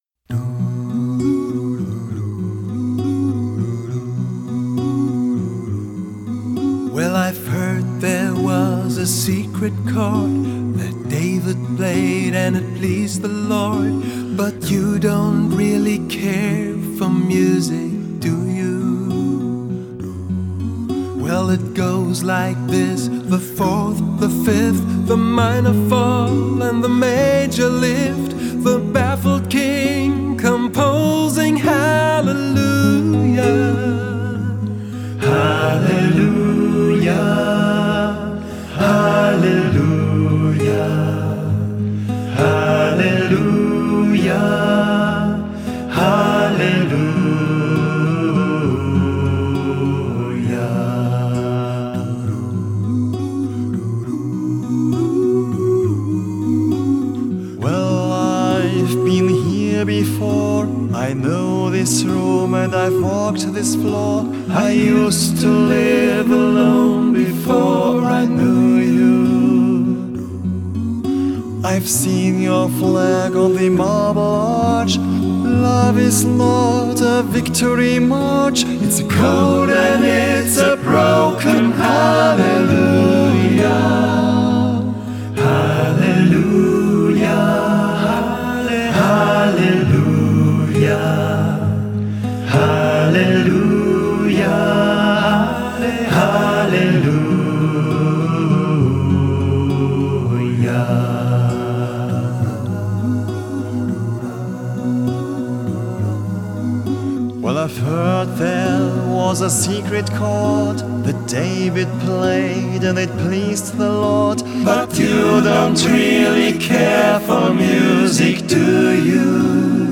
Genre: Gregorian chant / Pop-Mystic / Choral
/ Neo-Classical / New Age